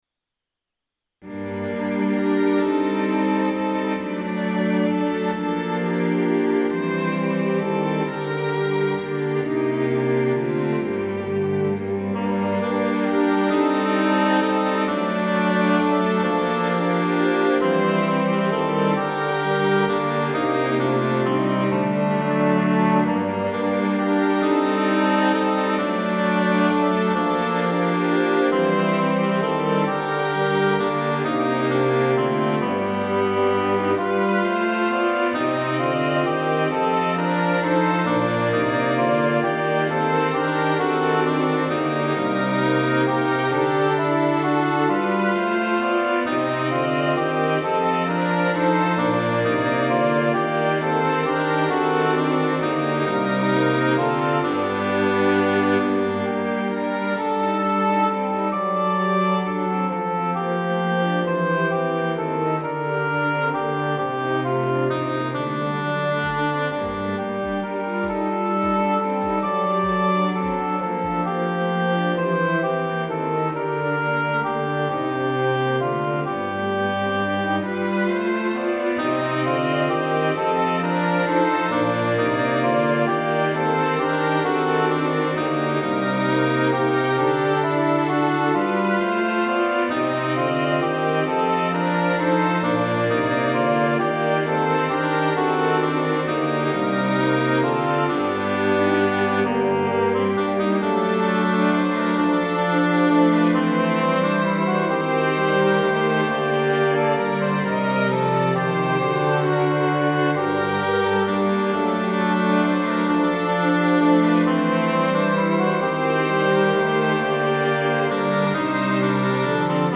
- 器楽付合唱編曲例
前奏あり
▼DL↓   1.0 フルート オーボエ クラリネット ファゴット